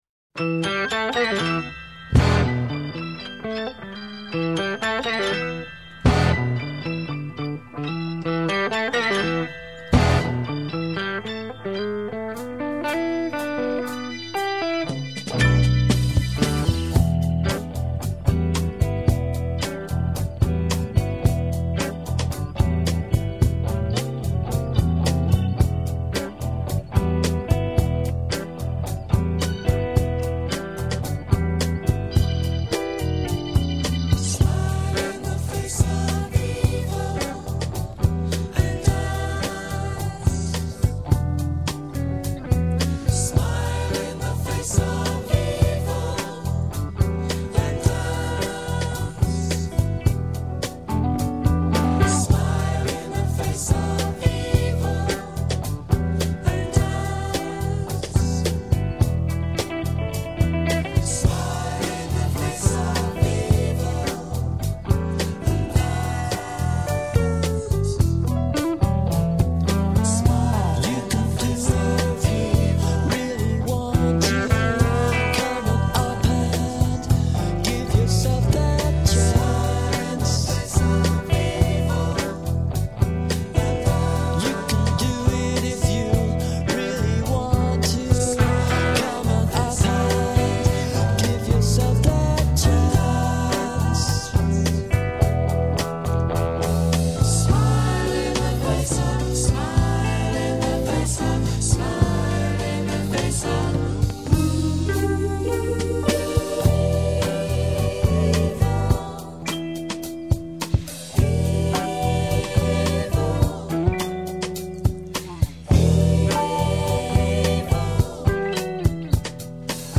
with some czech talking